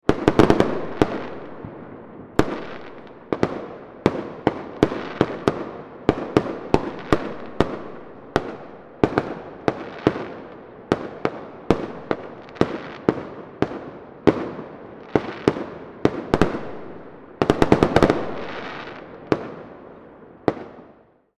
Continuous Firework Explosions Sound Effect
Description: Continuous firework explosions sound effect. 4th of July celebration or other festive event with street fireworks.
Continuous-firework-explosions-sound-effect.mp3